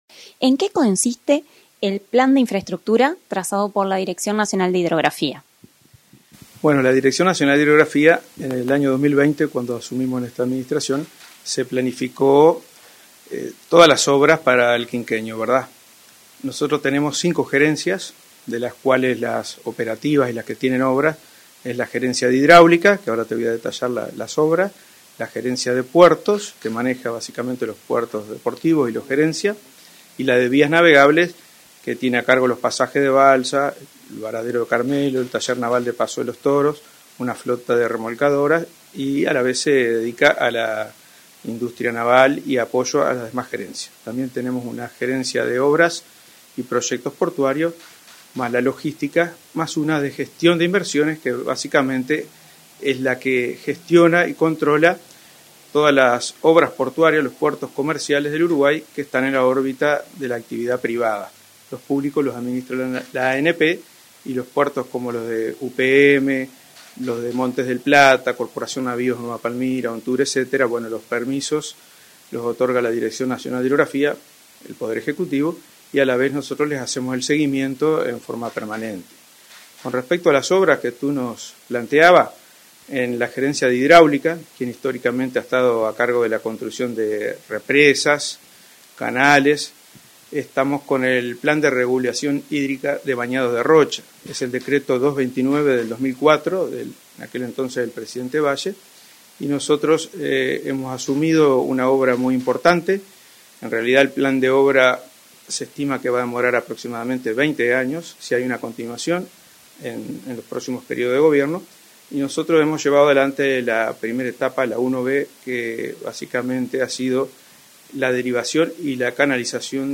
Entrevista al director nacional de Hidrografía, Marcos Paolini